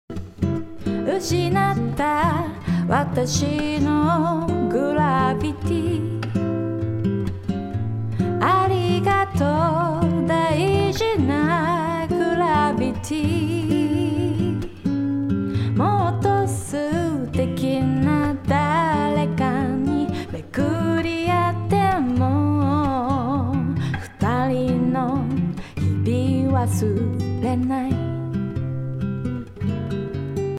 ダイナミックEQでやろうと思った案は、ボーカルと当たった瞬間のギターの音のある帯域を抑えることです。
歌をよりスッキリさせることができたような気がします。